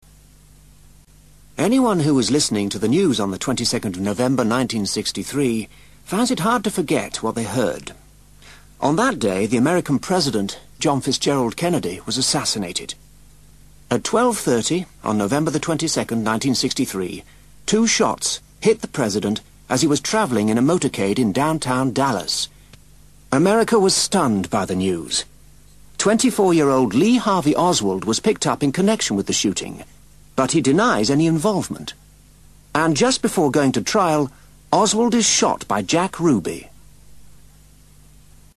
Note that the listening text includes actual news footage from the event, as it happened. This makes for exciting listening, but there is an inevitable loss of recording quality in places. You must also be prepared for a wide variety of voices, the American accents and some speedy delivery in the audios.
ACTIVITY 212: Listen to part of a radio program about Kennedy's assassination and then answer these questions by filling in the blanks below.